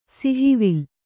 ♪ sihivil